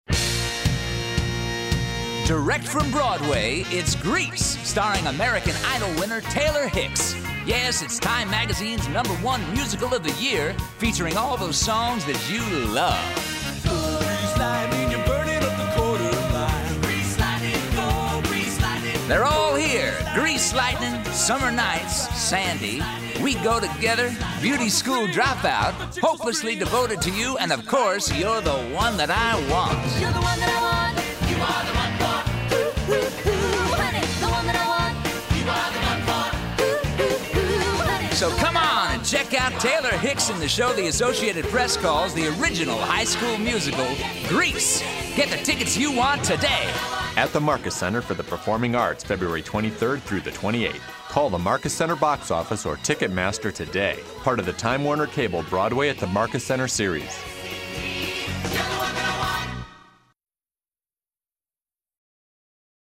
Grease Radio Commercial